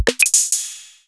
My first multisampled kit (808):
In this 808 kit your Kick is one of the quietest things, as AMP affects the whole kit this could be problematic.